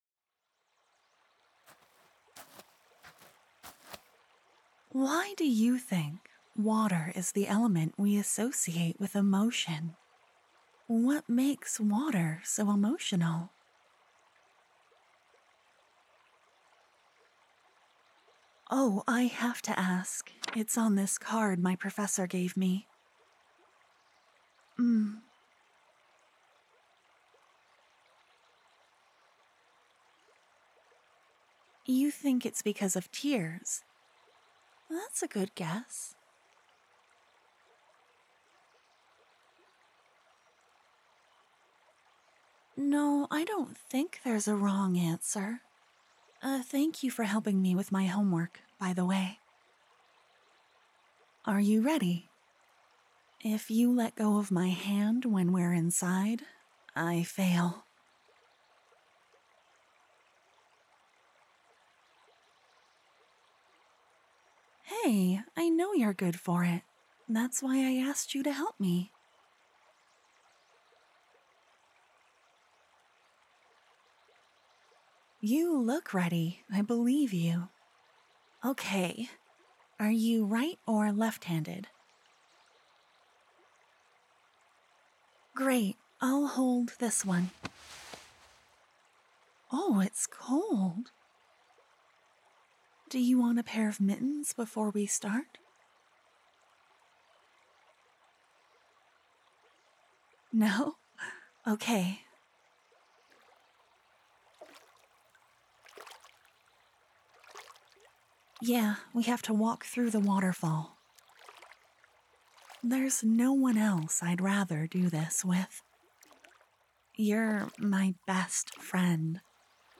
Downloads Download Water Mage Friend PATREON.mp3 Download Water Mage Friend PATREON LOUDER VOICE.mp3 Download Water Mage Friend PATREON.mp3 Download Water Mage Friend PATREON.mp3 Content Been a while since I did a platonic, gentle adventure audio~! Your best friend needs your help with her final exam: exploring a water dungeon with themes on friendship...
I think you'll enjoy the different environments and peaceful water magic~ You'll see this video on YouTube this Thursday~ XOXO Files